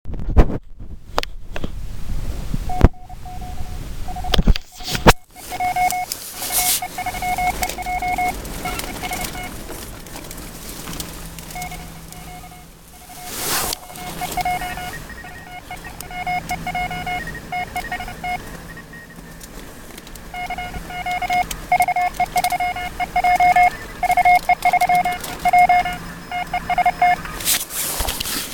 Да и аудио- запись, как была слышна канадская станция на самоделке, в лесах-полях, тоже постараюсь приложить.